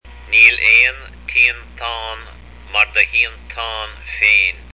There is no hearth like your own hearth, or no place like home. Learn how to pronounce it below: